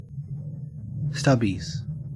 Ääntäminen
Ääntäminen AU Haettu sana löytyi näillä lähdekielillä: englanti Käännöksiä ei löytynyt valitulle kohdekielelle.